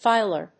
/ˈfaɪlɝ(米国英語), ˈfaɪlɜ:(英国英語)/